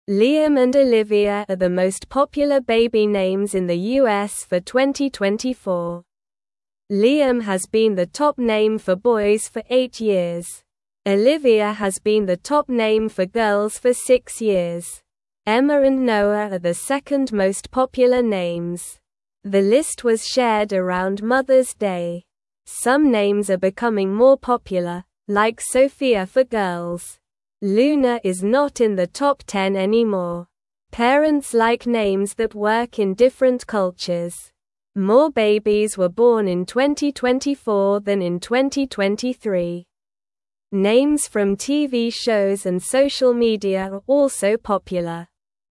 Slow
English-Newsroom-Beginner-SLOW-Reading-Liam-and-Olivia-Are-Top-Baby-Names-for-2024.mp3